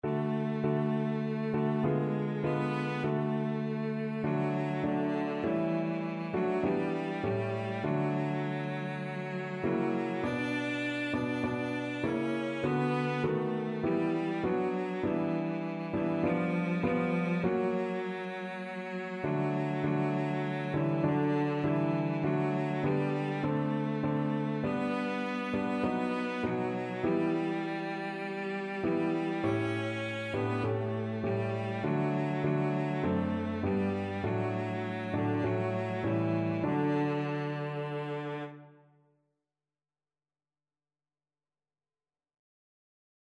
Christian
4/4 (View more 4/4 Music)
Classical (View more Classical Cello Music)